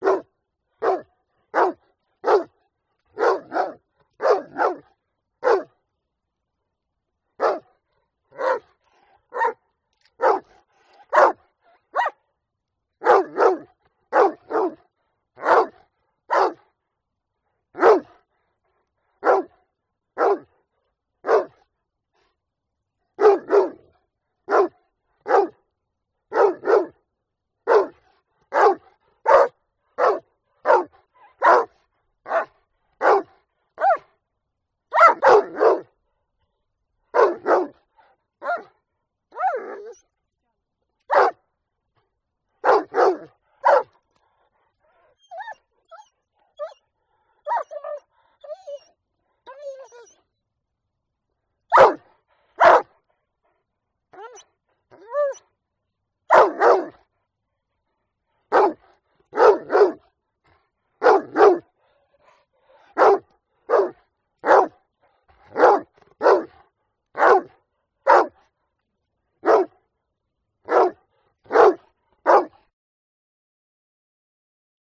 دانلود صدای پارس سگ ترسناک از ساعد نیوز با لینک مستقیم و کیفیت بالا
جلوه های صوتی